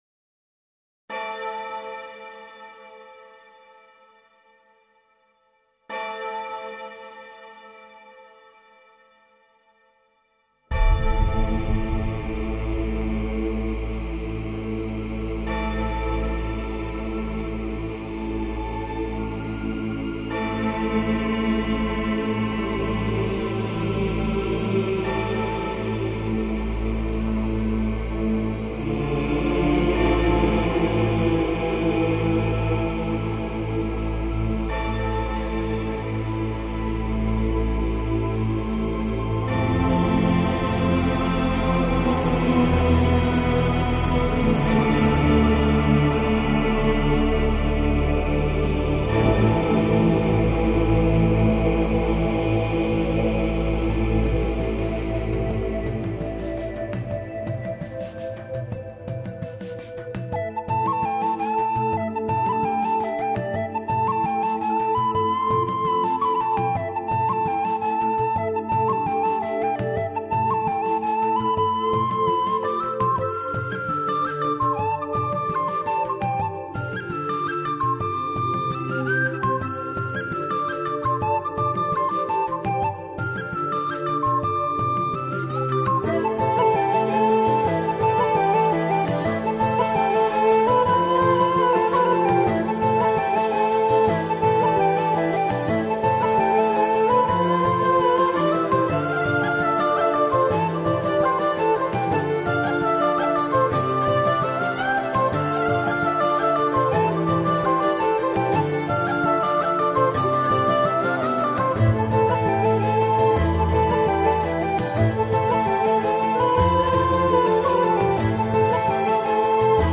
这是一场相当大规模的户外演出，由两个舞蹈团共84位舞者参加演出，
还有独唱、小提琴合奏、风笛等其它表演作为辅助手段。
˙鏗鏘有力的踢踏舞聲慧如火焰般地烙印心扉
鼓聲　依然厚實　笛聲　依舊輕颺
琴聲　依然悽美　歌聲　依舊清淨